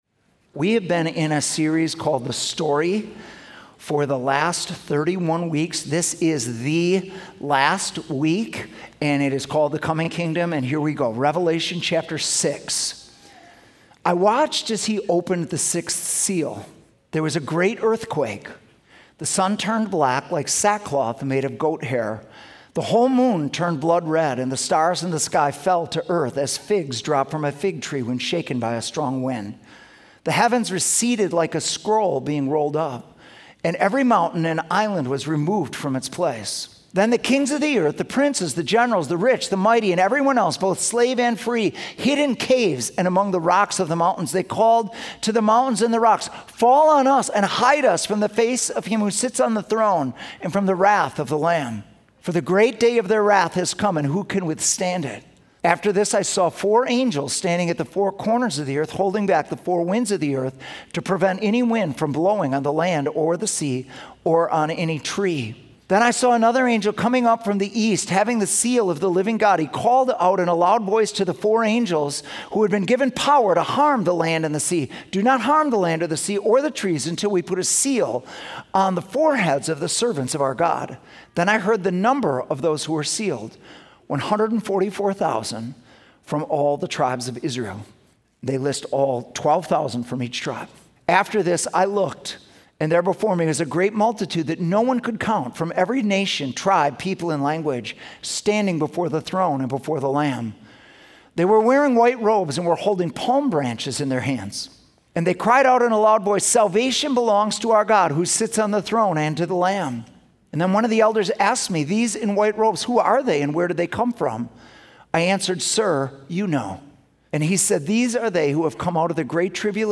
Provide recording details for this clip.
Sunday Messages @ City Church